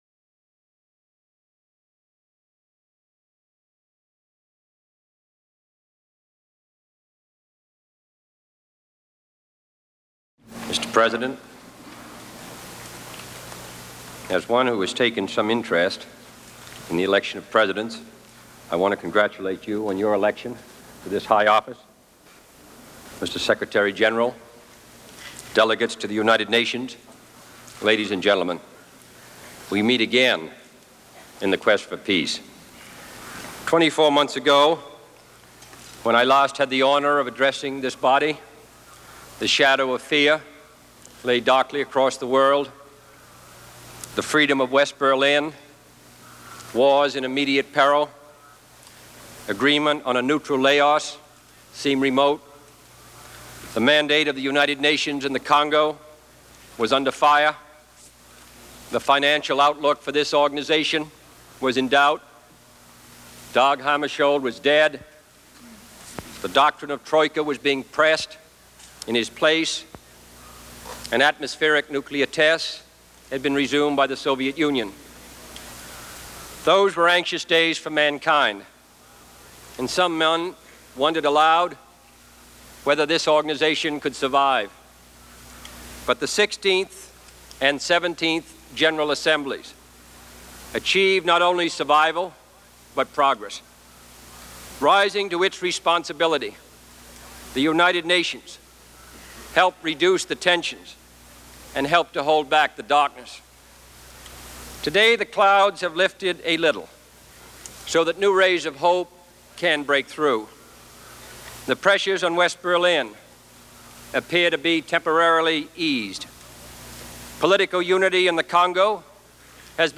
September 20, 1963: Address to the UN General Assembly